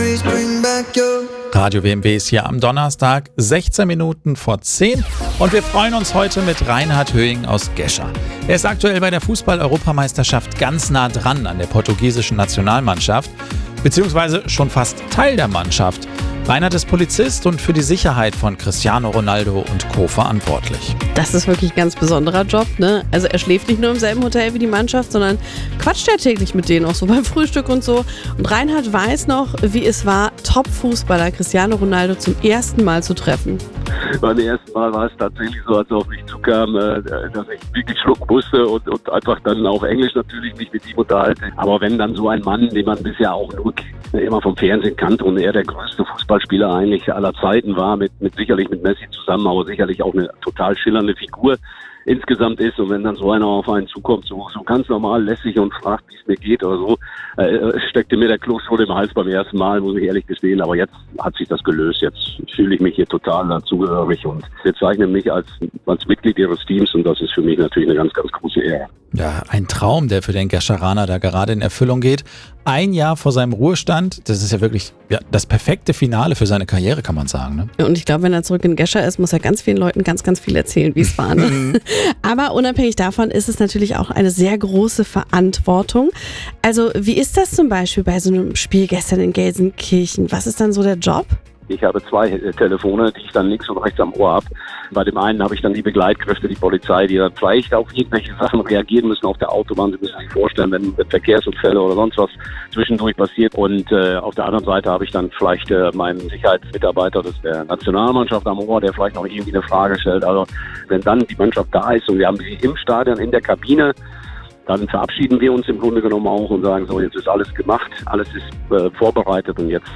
Der RADIO WMW Directors Cut – das ungefilterte Interview.